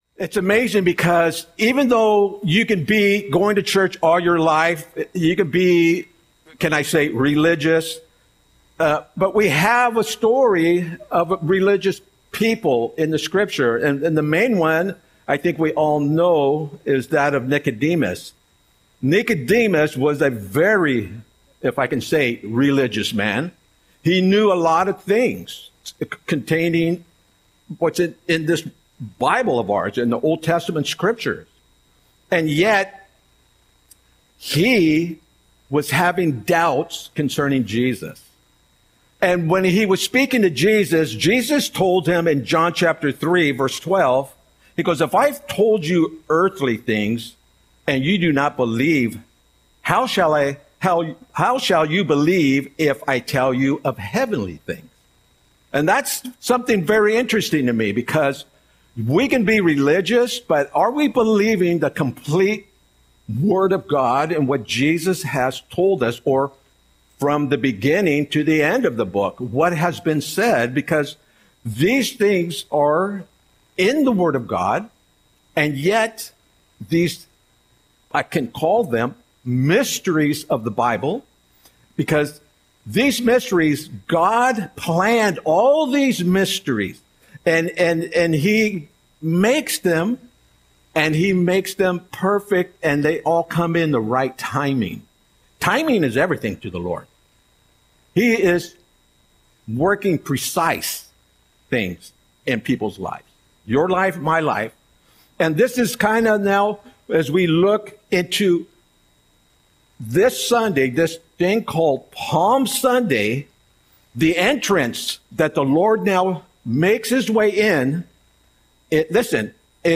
Audio Sermon - April 13, 2025